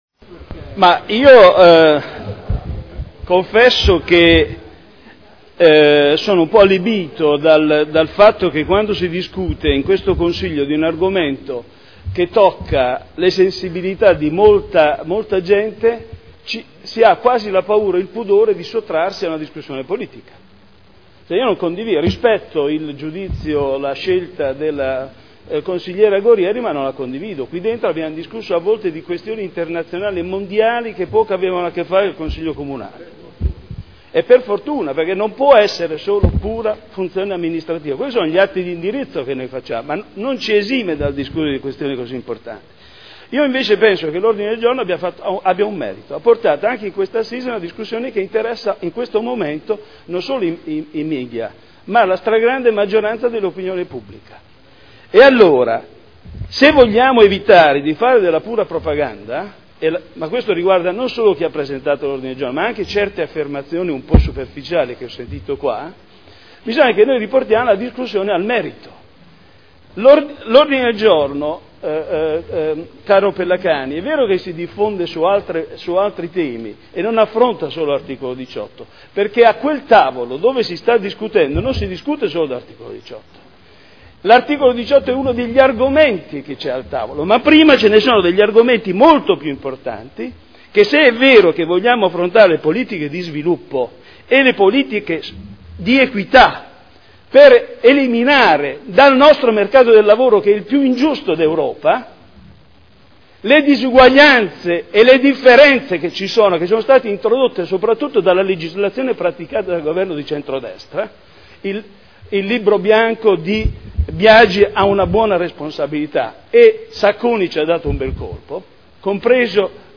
Michele Andreana — Sito Audio Consiglio Comunale
Seduta del 27 febbraio. Mozione presentata dai consiglieri Ricci (Sinistra per Modena) e Trande (P.D.) avente per oggetto: “Riforma del “mercato del lavoro” e Articolo 18 dello Statuto dei Lavoratori: diritti dei lavoratori, delle lavoratrici e relazione con la crescita e occupazione” Dibattito